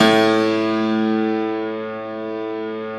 53a-pno05-A0.aif